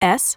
OCEFIAudio_en_LetterS.wav